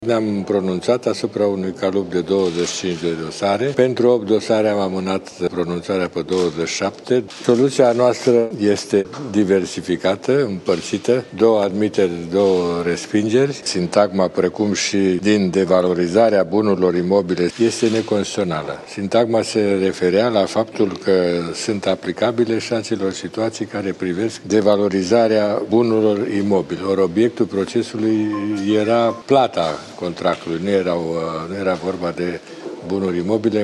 Unele sesizări depuse de bănci au fost admise, altele, respinse – a spus președintele Curții Constituționale, Valer Dorneanu.